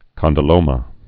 (kŏndl-ōmə)